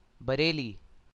Bareilly (Hindi: Barēlī, pronounced [bəɾeːliː]